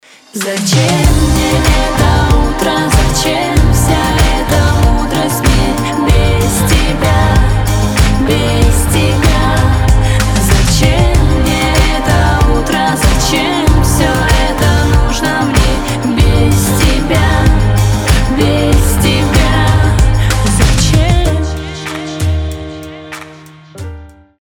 • Качество: 320, Stereo
поп
романтичные
медляк